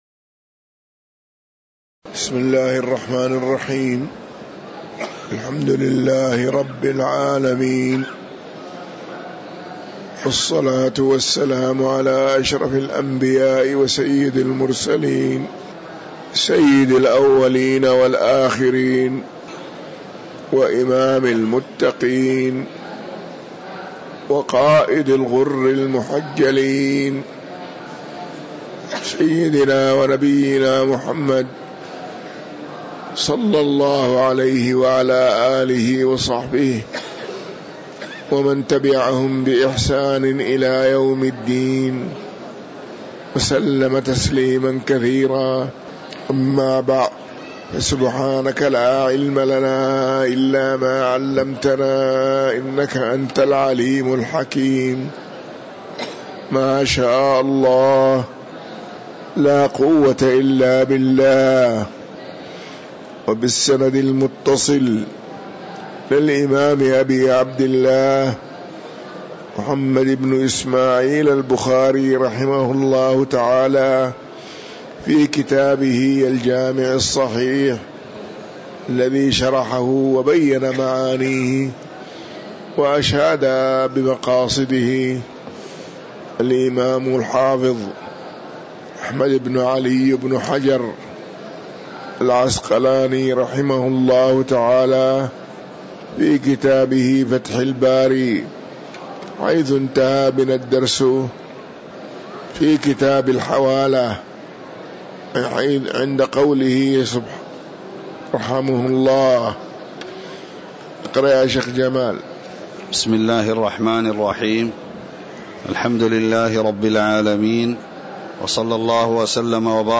تاريخ النشر ٢٨ جمادى الأولى ١٤٤٥ هـ المكان: المسجد النبوي الشيخ